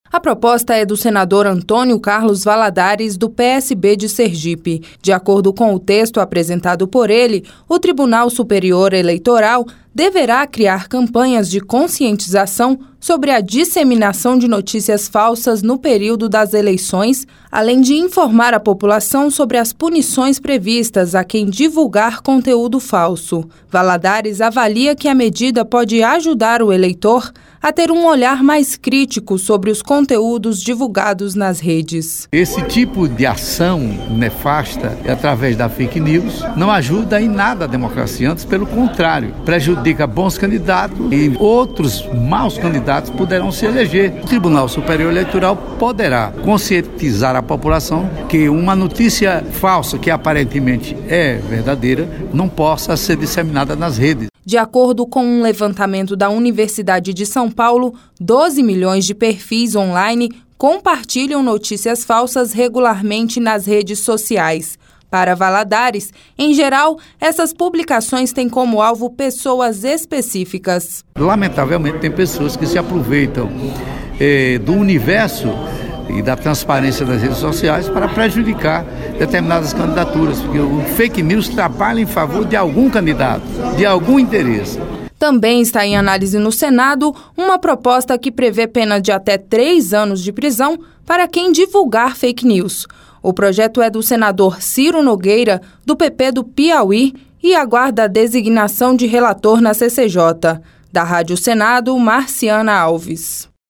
As informações são da repórter